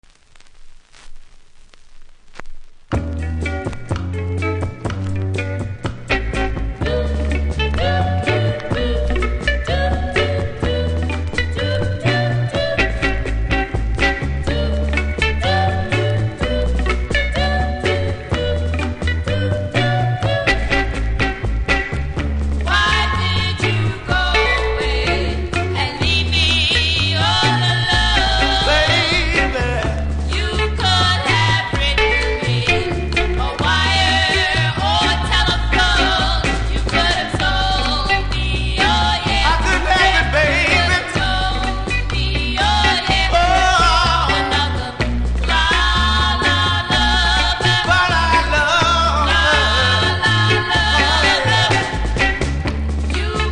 見た目は綺麗ですが、プレス起因で出だしノイズありますので試聴で確認下さい。